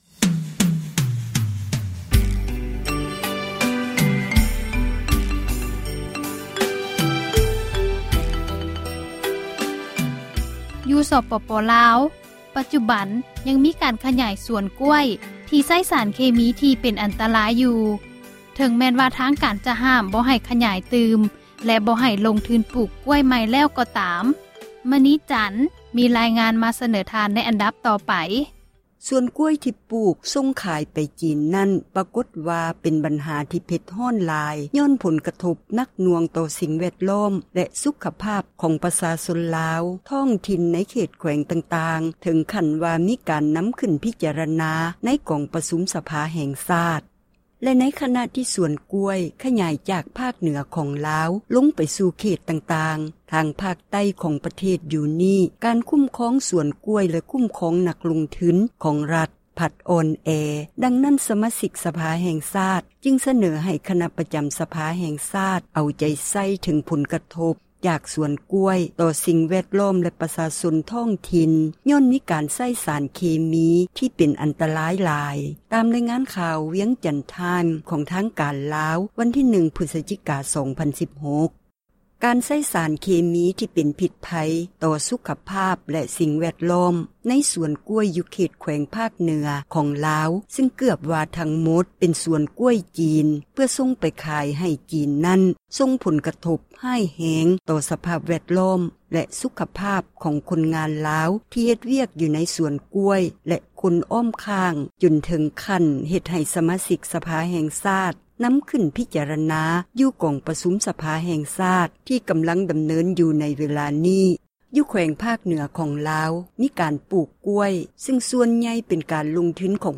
ຈີນ ຂຍາຍສວນກ້ວຍແລະໃຊ້ ເຄມີ — ຂ່າວລາວ ວິທຍຸເອເຊັຽເສຣີ ພາສາລາວ